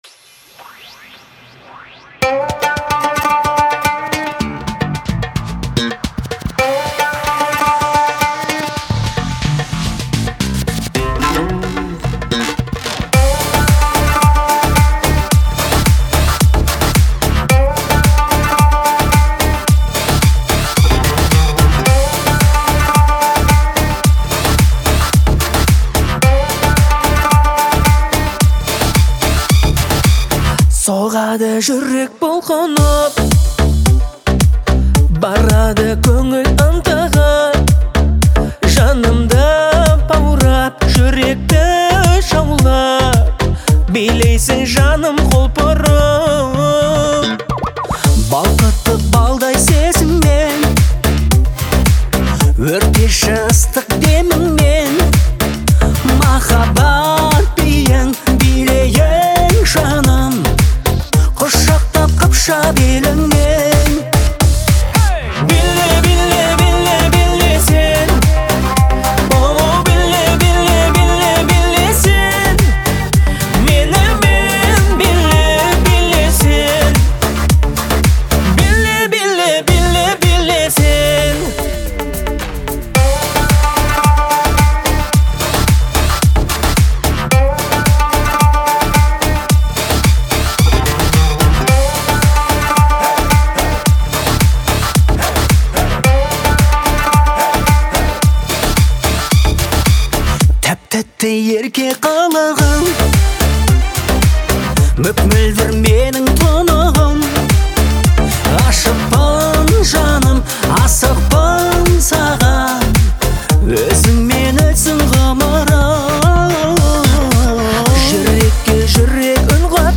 живыми инструментами и зажигательными ритмами